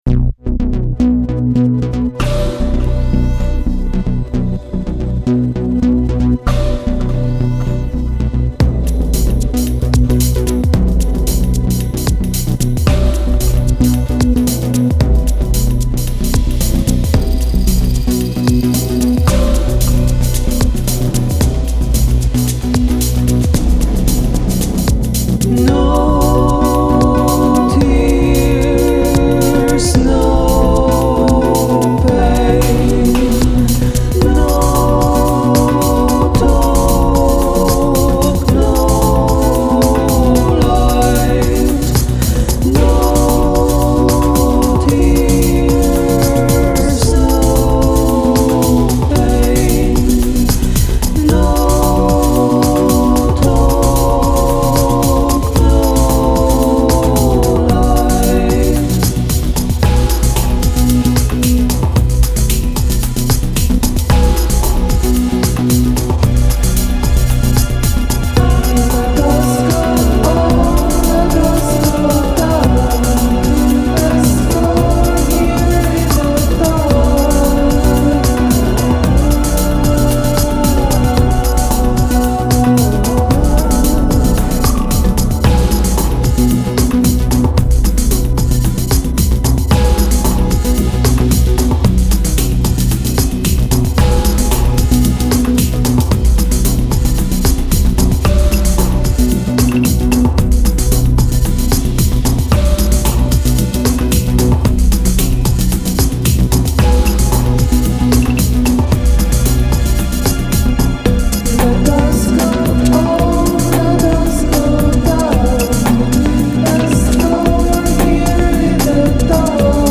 experimental electronica